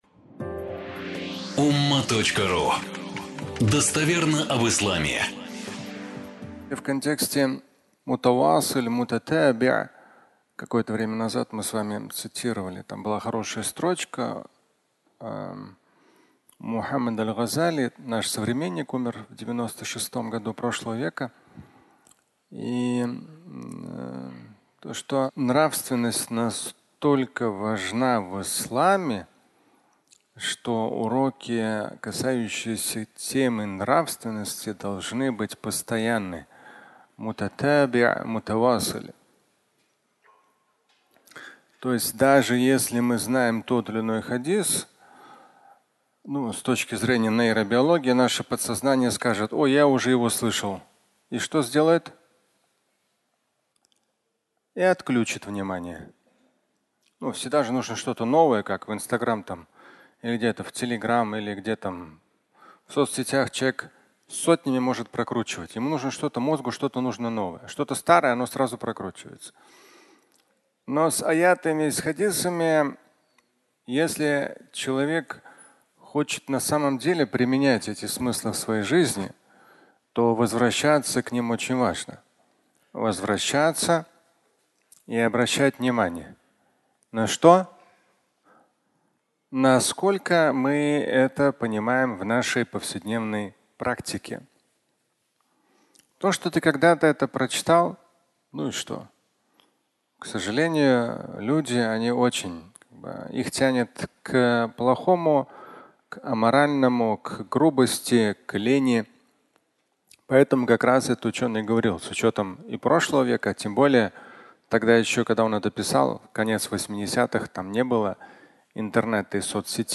Потерять все (аудиолекция)
Фрагмент пятничной лекции